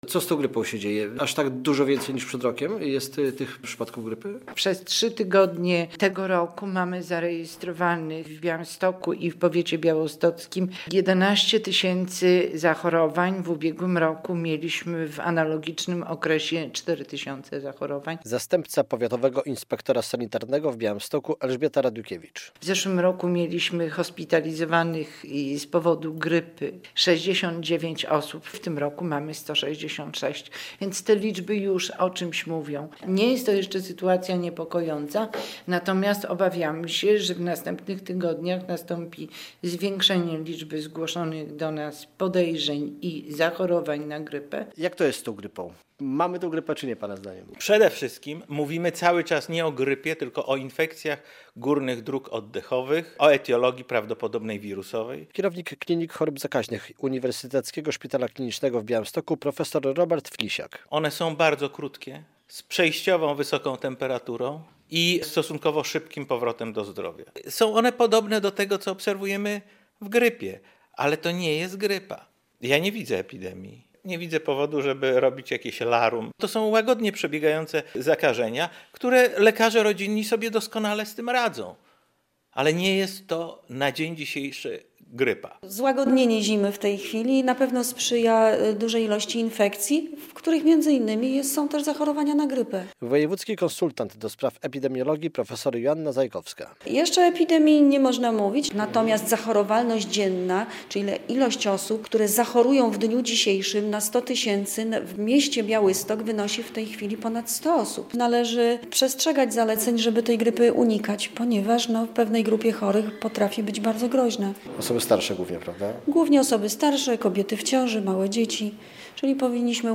Radio Białystok | Wiadomości | Wiadomości - Region: Rośnie liczba chorych na infekcje górnych dróg oddechowych